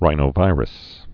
(rīnō-vīrəs)